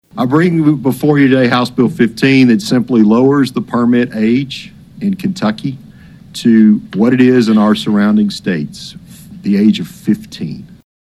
Representative Steven Rudy presented the bill during a meeting of the House Transportation Committee.(AUDIO)